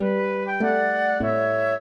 flute-harp